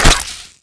rifle_hit_flesh1.wav